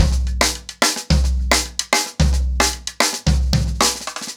TimeToRun-110BPM.45.wav